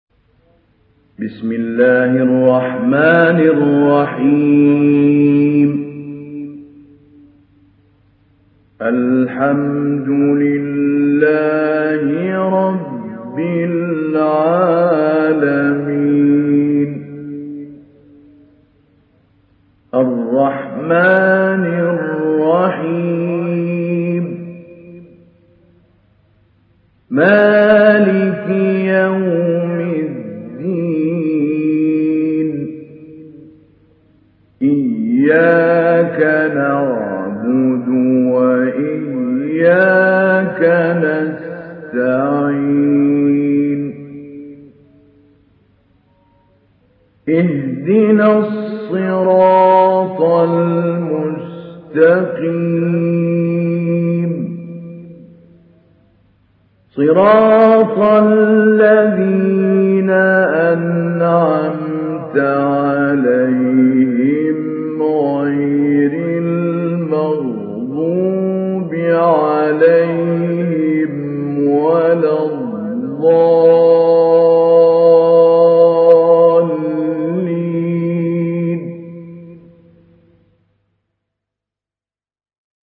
تحميل سورة الفاتحة mp3 بصوت محمود علي البنا مجود برواية حفص عن عاصم, تحميل استماع القرآن الكريم على الجوال mp3 كاملا بروابط مباشرة وسريعة
تحميل سورة الفاتحة محمود علي البنا مجود